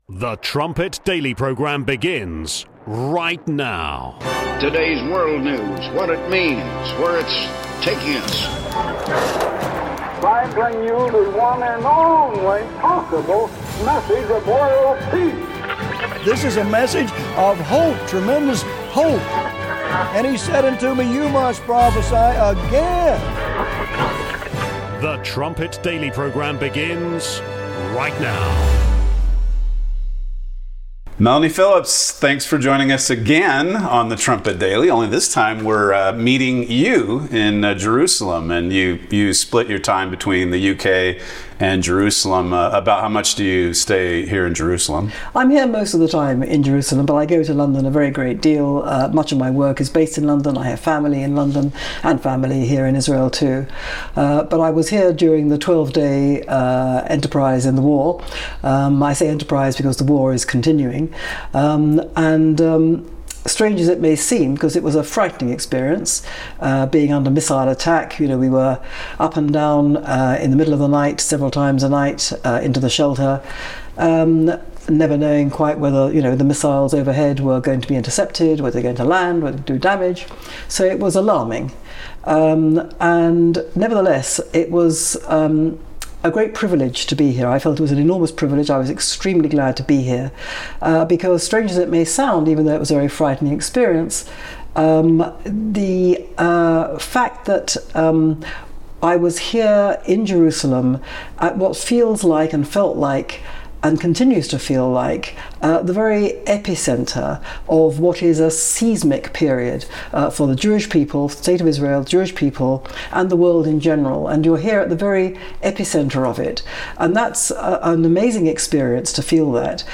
00:30 Trumpet Daily Interview: Melanie Phillips (55 minutes)
trumpet-daily-2598-interview-with-melanie-phillips.mp3